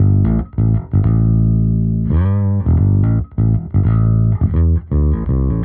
Index of /musicradar/dusty-funk-samples/Bass/85bpm
DF_JaBass_85-A.wav